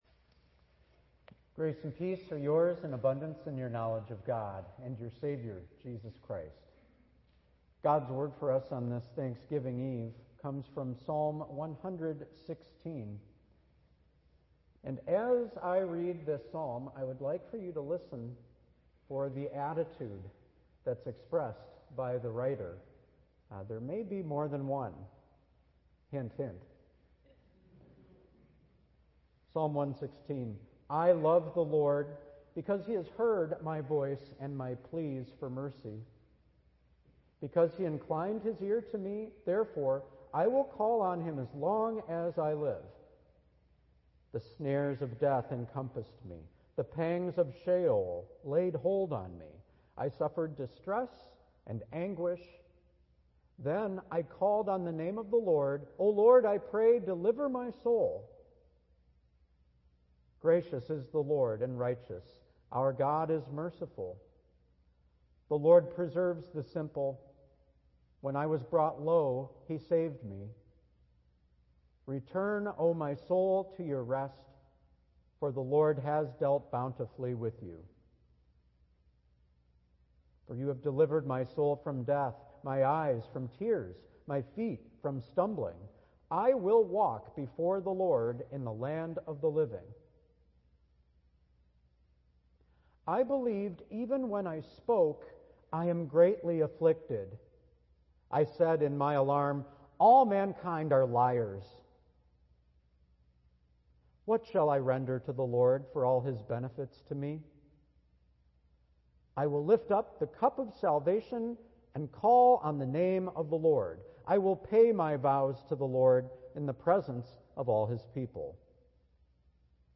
Thanksgiving